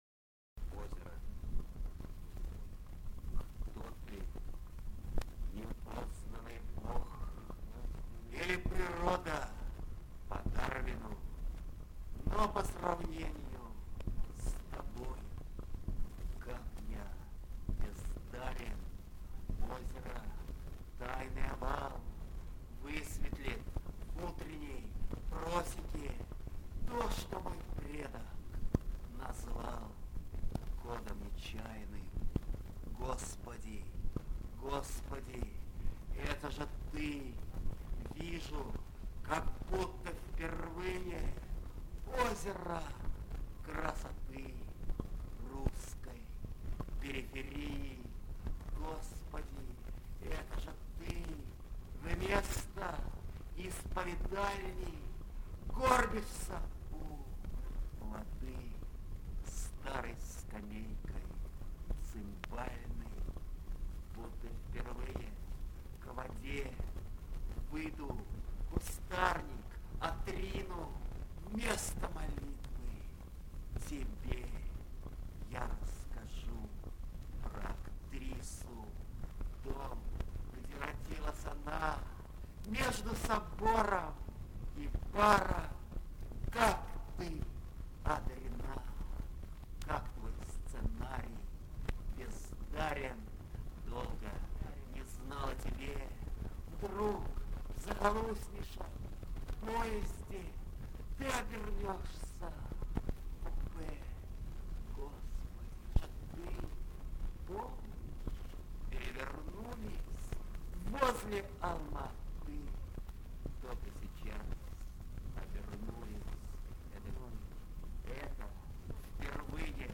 andrej-voznesenskij-ozero-chitaet-avtor